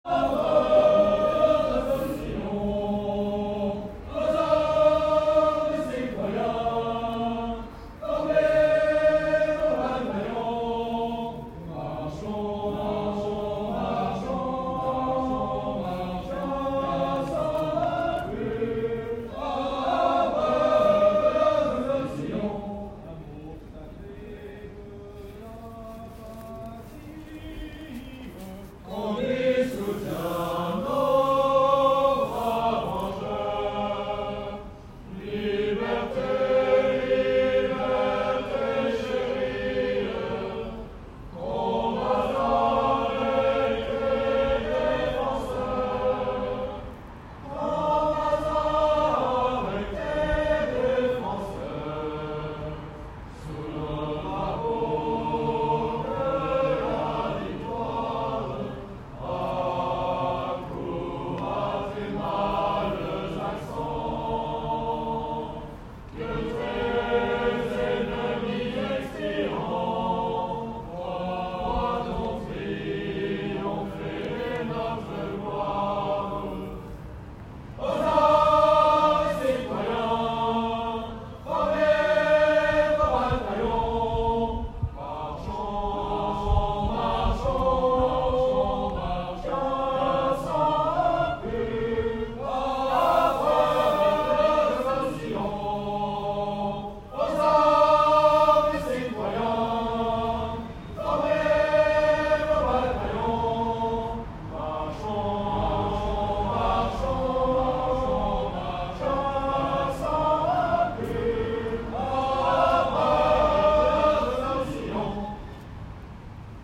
Paris 21 rue Descartes